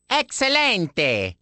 One of Luigi's voice clips from the Awards Ceremony in Mario Kart: Double Dash!!